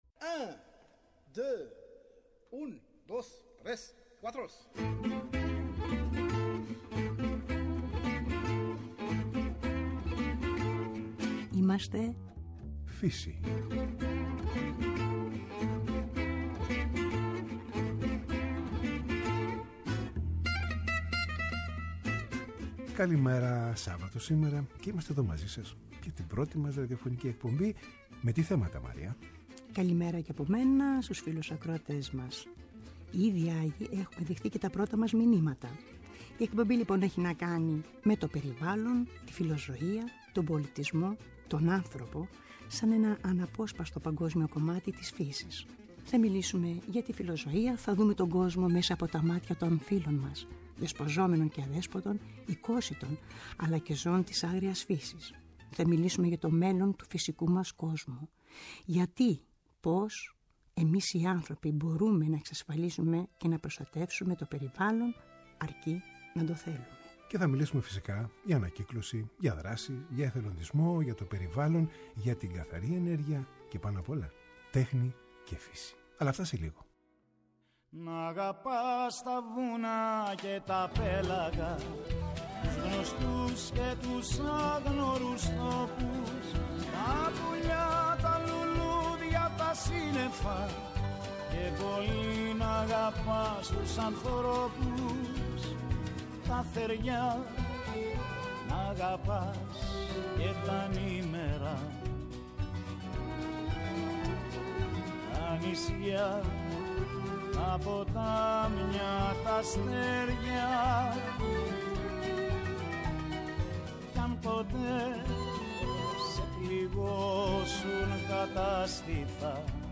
είμαστε Φύση Σάββατο 09.03.19 στις 09:00πμ Δεύτερο Πρόγραμμα 103,7 Η πρεμιέρα της ραδιοφωνικής μας εκπομπής. Τηλεφωνική συνέντευξη καλωσόρισμα από τον κ Παντελή Θαλασσινό